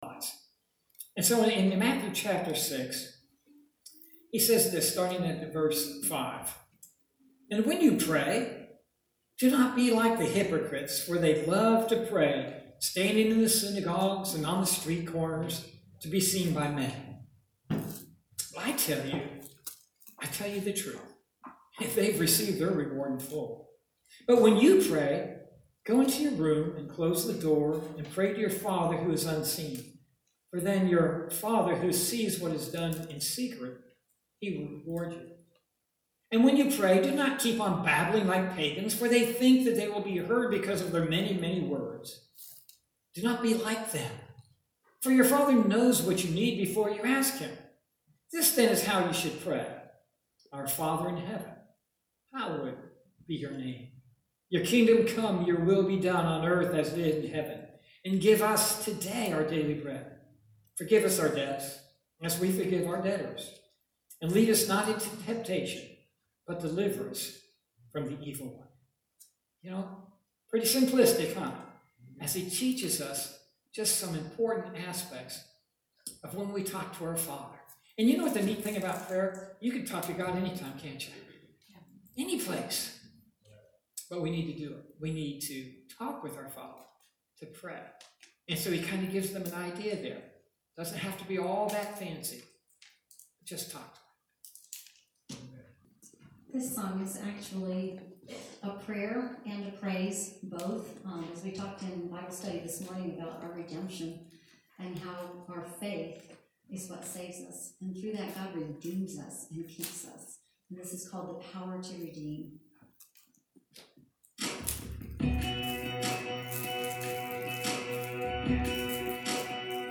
Sermons - Parkland Baptist Church